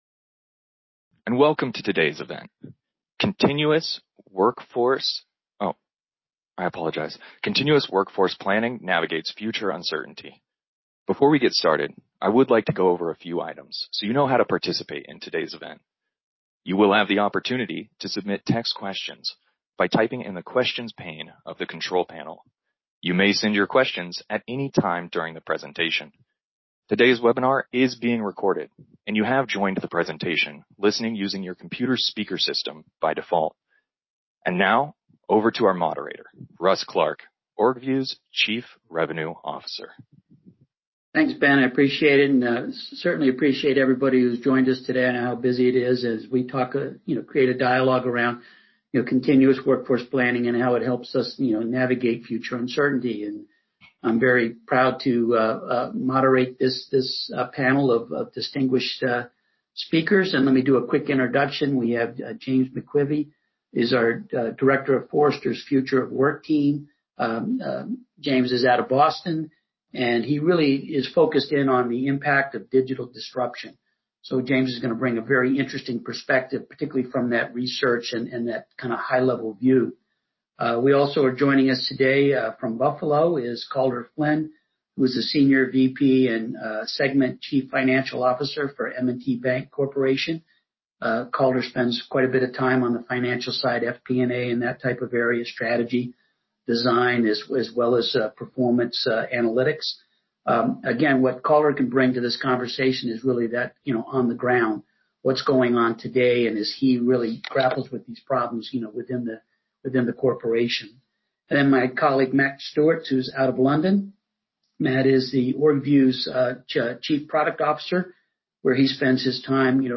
Thank you for downloading the on-demand webinar
Webinar-How-to-navigate-future-uncertainty-with-workforce-planning.mp3